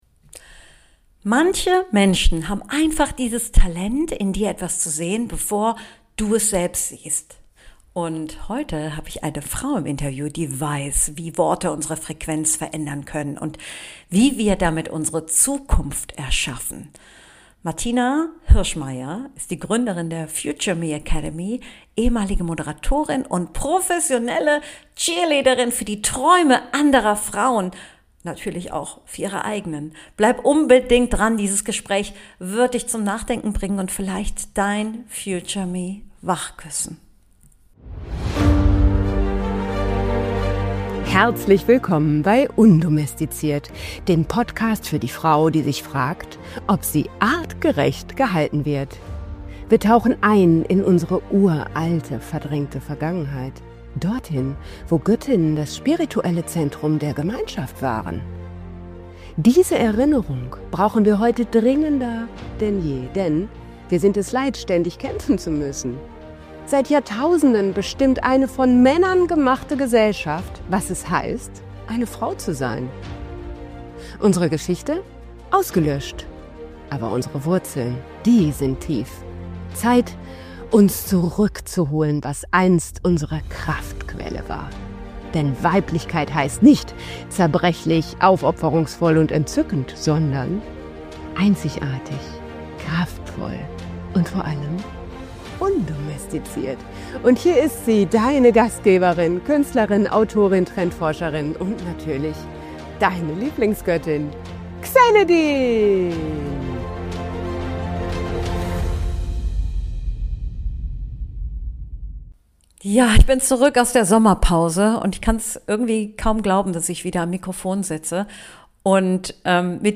#65 Gespräch mit deinem Future Me – wie deine Worte deine Zukunft formen.